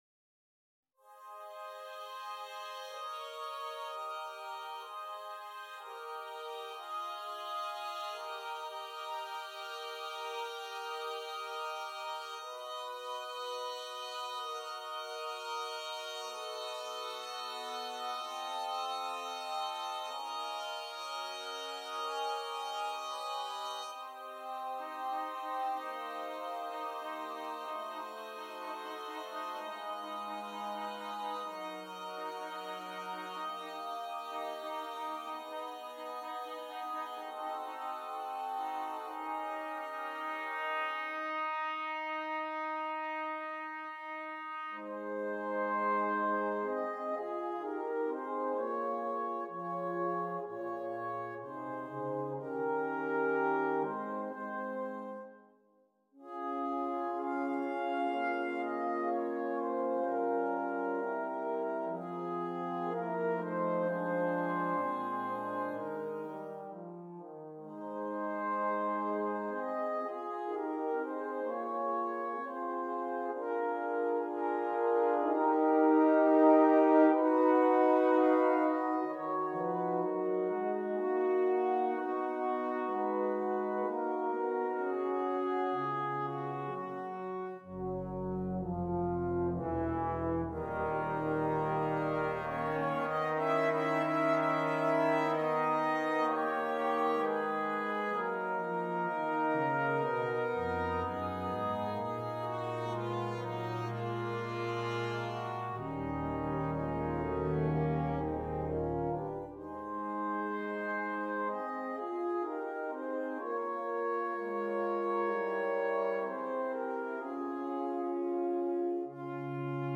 Besetzung: Vocal Solo & Brass Band